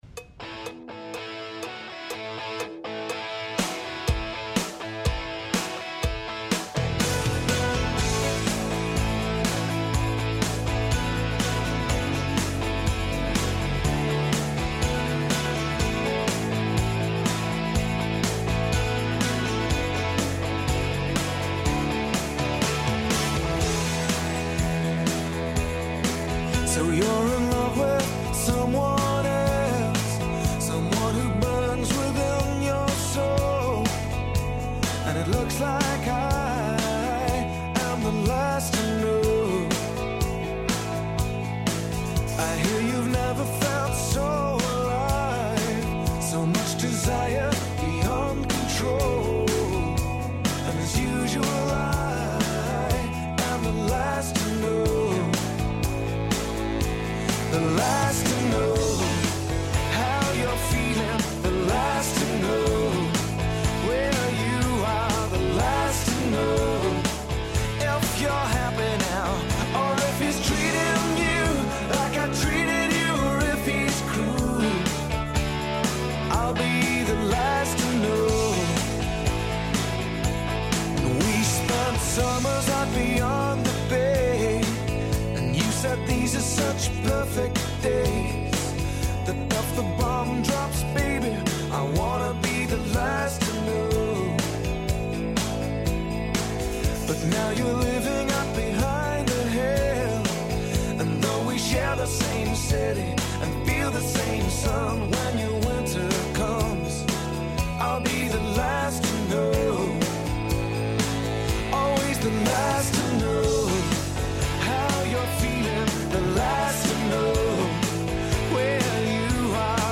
Време посвећујемо музици, пре свега. Чућете приче које стоје иза песама, новости са светских топ листа, као и неке од важних догађаја из света музике који су се одиграли на данашњи дан.